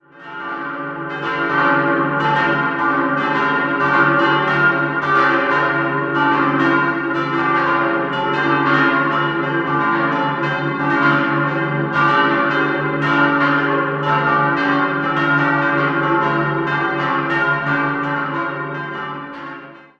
5-stimmiges Geläute: cis'-d'-f'-g'-a'
Ein sehr spezielles Quintett, das mit einem (verengten) Halbton zwischen den beiden Grundglocken abschließt.